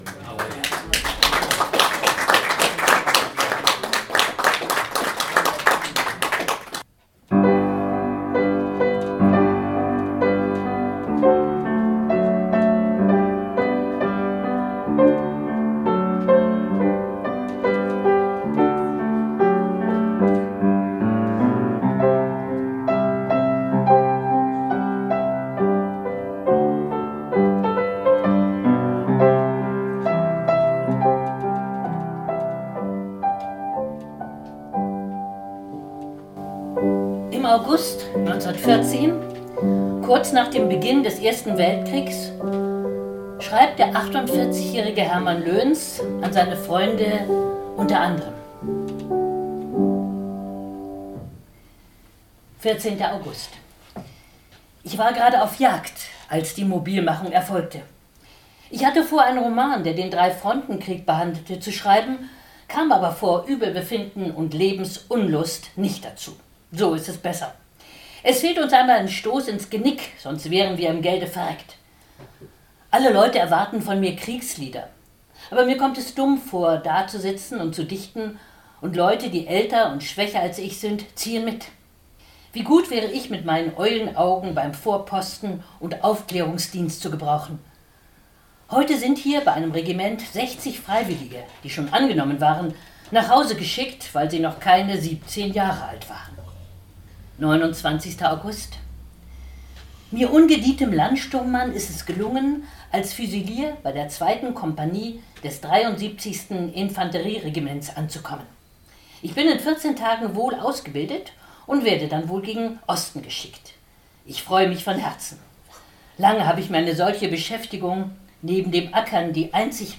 Mitschnitt einer öffentlichen Veranstaltung (MP3, Audio)